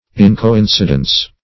Search Result for " incoincidence" : The Collaborative International Dictionary of English v.0.48: Incoincidence \In`co*in"ci*dence\, n. The quality of being incoincident; lack of coincidence.